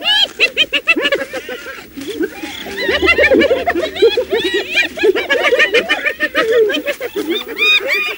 На этой странице собраны разнообразные звуки гиены: от характерного смеха до агрессивного рычания.
Смех гиенового стада звук